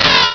pokeemerald / sound / direct_sound_samples / cries / shedinja.aif